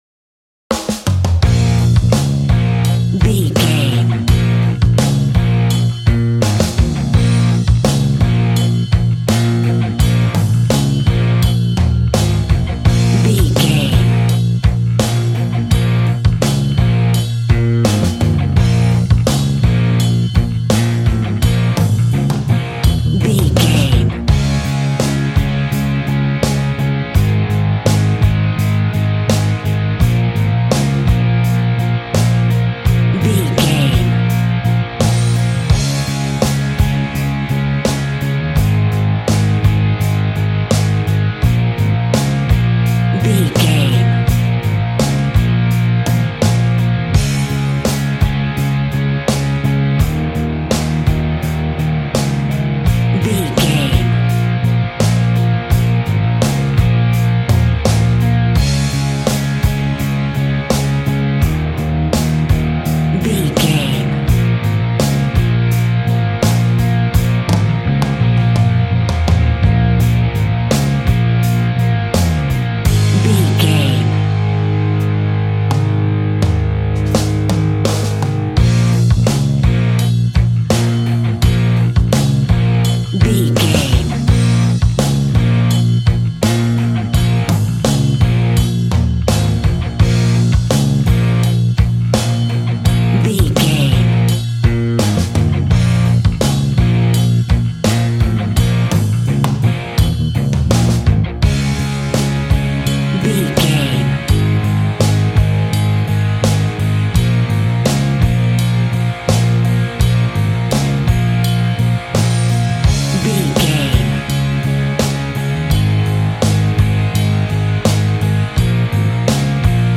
Aeolian/Minor
B♭
hard
groovy
powerful
electric guitar
bass guitar
drums
organ